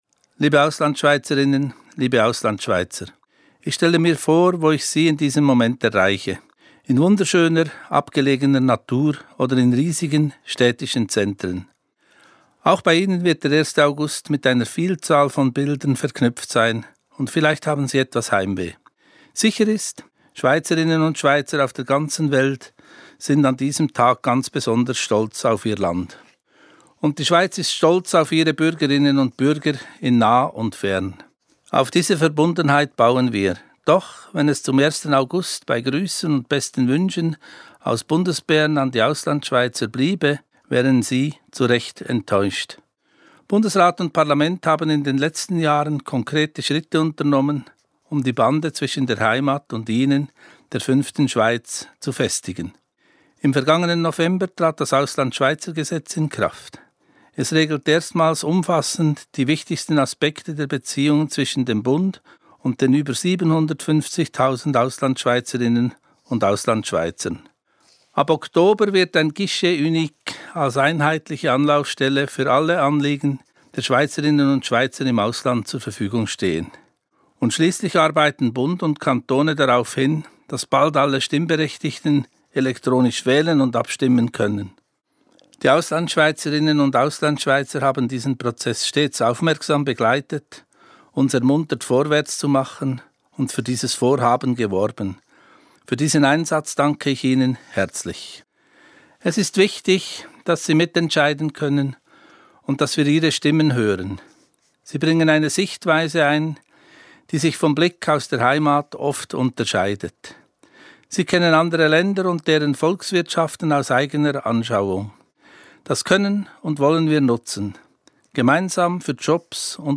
Rede Bundespräsidenten an Auslandschweizer zum Nationalfeiertag